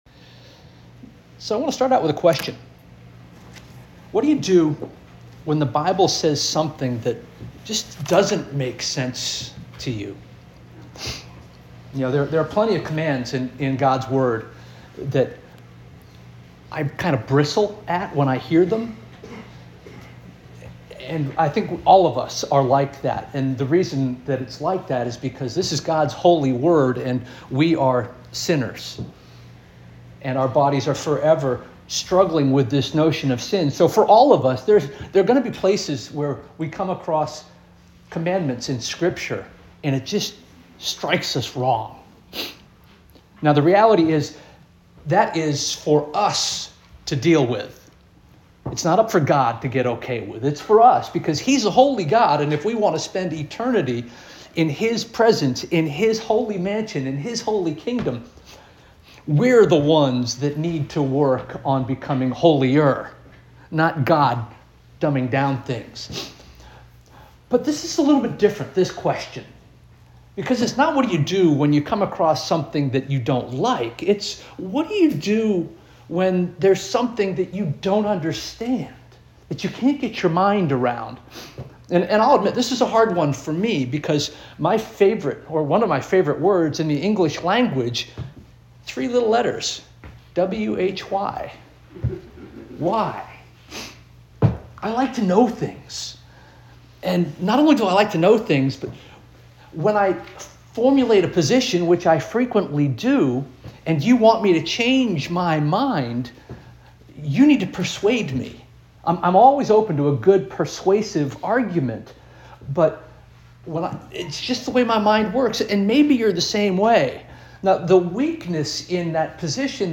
February 1 2026 Sermon - First Union African Baptist Church